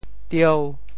“条”字用潮州话怎么说？
条（條） 部首拼音 部首 木 总笔划 7 部外笔划 3 普通话 tiáo tiāo 潮州发音 潮州 dieu5 文 中文解释 条 <名> (形声。